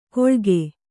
♪ koḷge